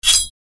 This Sword Sound Effects Collection includes great Sounds for creating the metal clangs of a Sword Fight and Swooshes of the Blade through the air!
Knightly-sword-blade-scrape-2.mp3